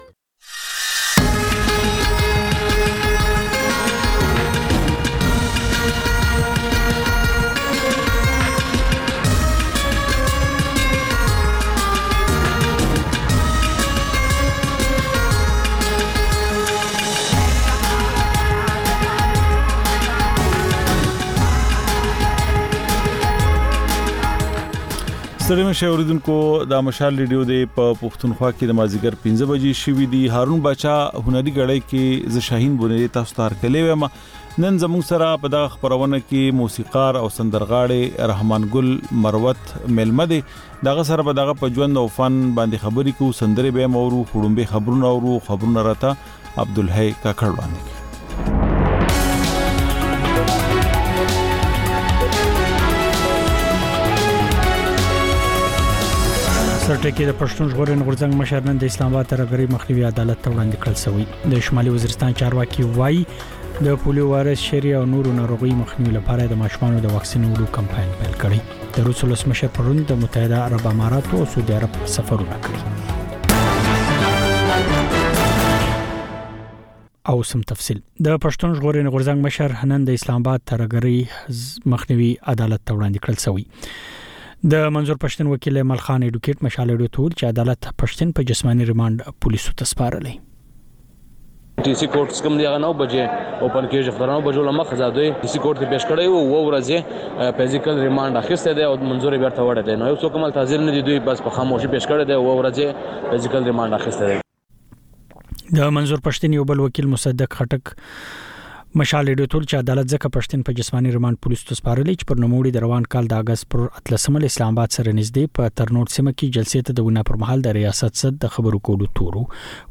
د مشال راډیو ماښامنۍ خپرونه. د خپرونې پیل له خبرونو کېږي، بیا ورپسې رپورټونه خپرېږي.